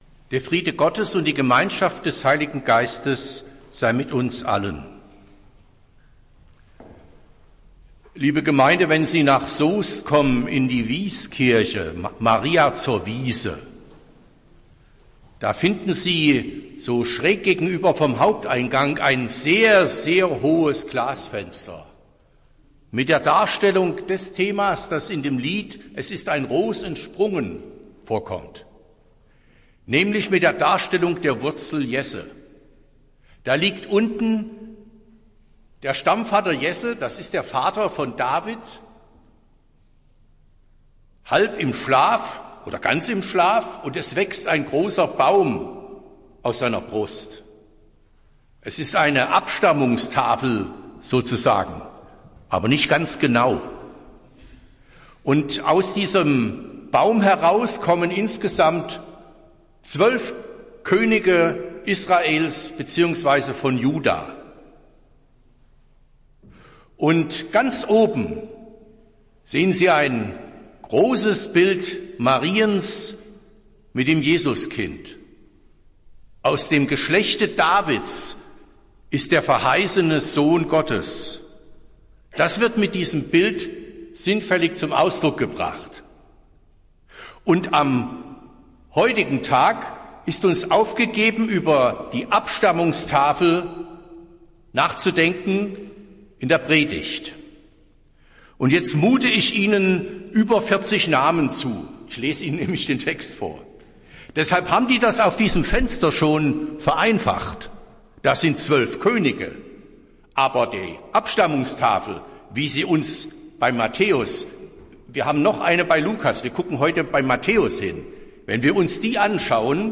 Predigt des Gottesdienstes aus der Zionskirche zum 2. Weihnachtstag